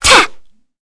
Kara-Vox_Attack4_kr.wav